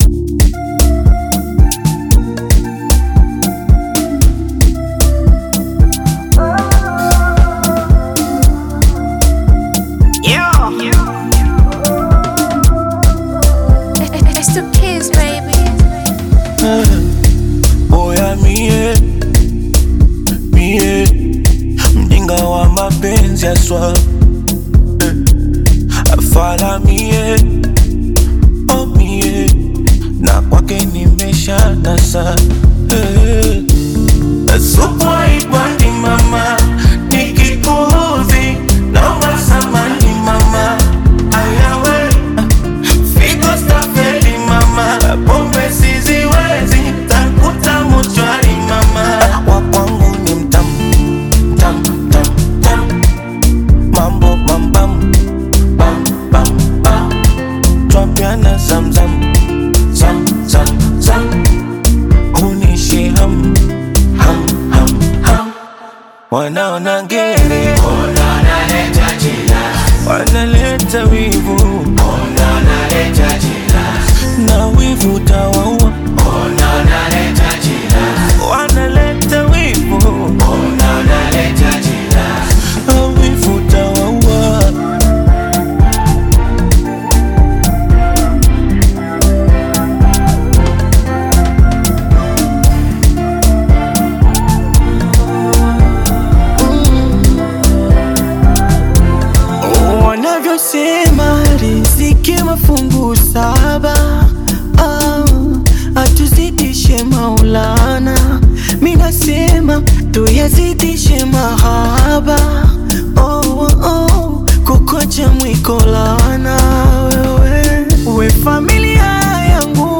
smooth Afro-Pop/Bongo Flava single
Genre: Amapiano